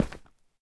assets / minecraft / sounds / step / stone1.ogg
stone1.ogg